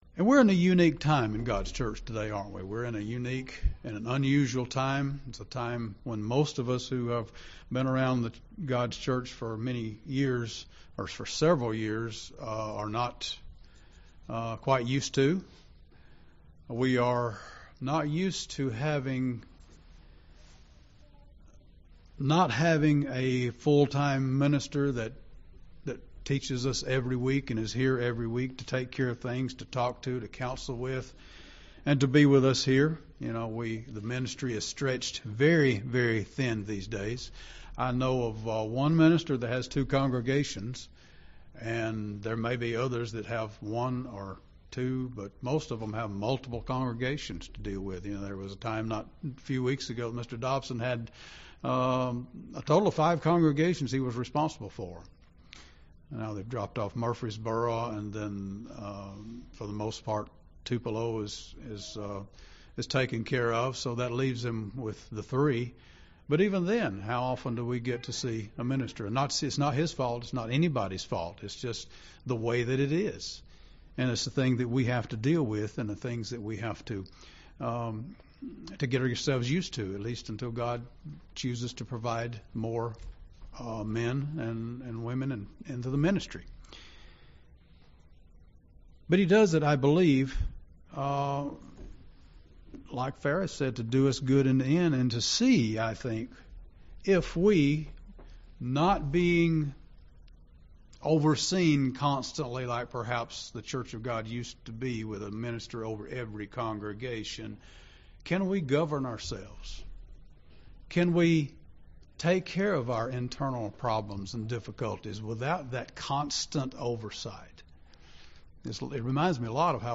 We each have a responsibility to promote unity and peace within the body of Christ. This sermon examines this topic and discusses 7 points to bring us to a more unified and peaceful body.
Given in Gadsden, AL